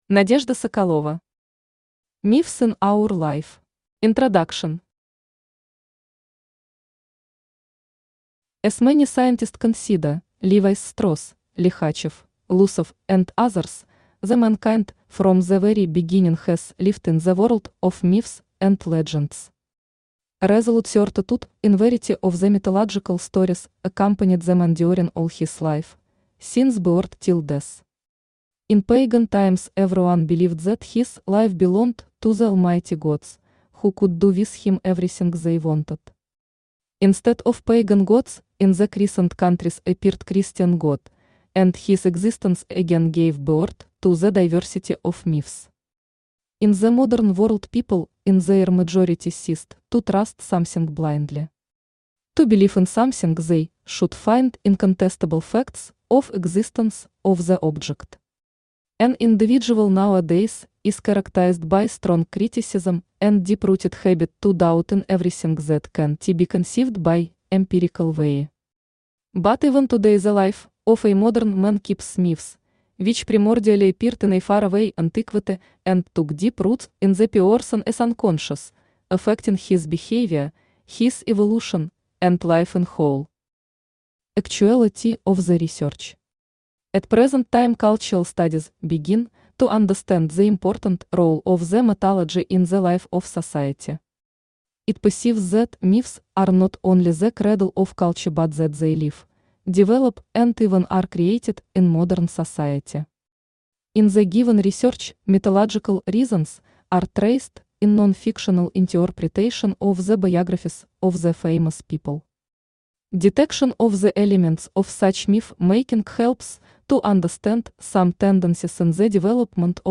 Aудиокнига Myths in our Life Автор Надежда Игоревна Соколова Читает аудиокнигу Авточтец ЛитРес.